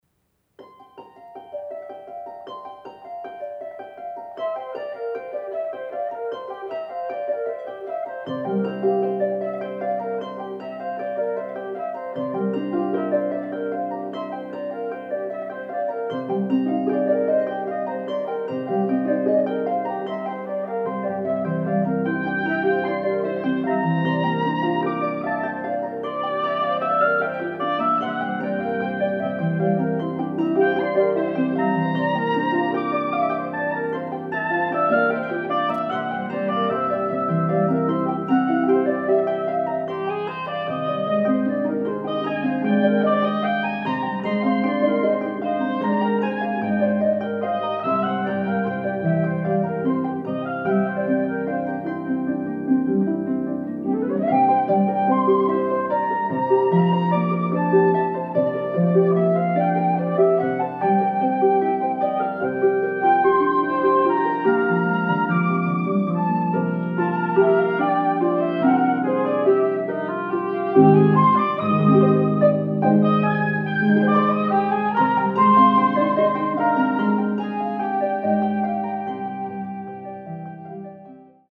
EPISODES FOR FLUTE, OBOE & HARP